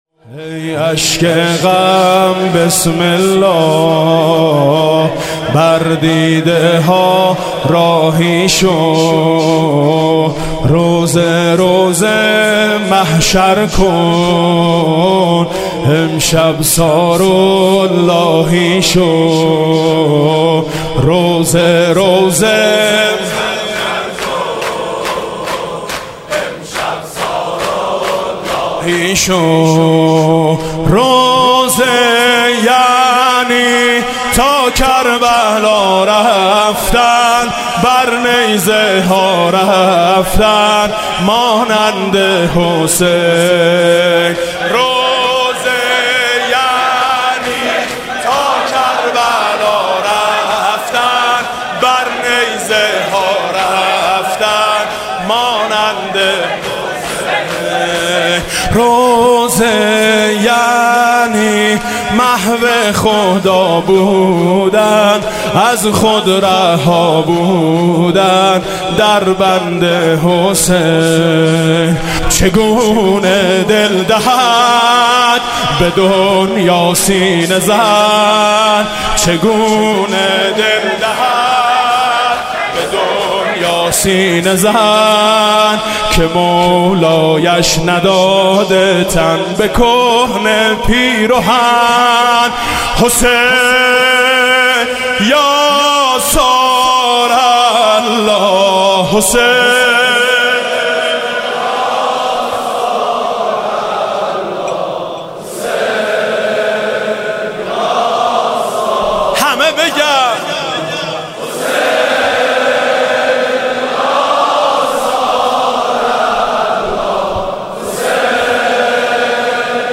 مداحی شب اول محرم + صوت
در اولین شب از ماه محرم می‌توانید، ذکر مصیبت و مداحی چند تن از مداحان اهل بیت (ع) را در این گزارش بشنوید و دانلود کنید.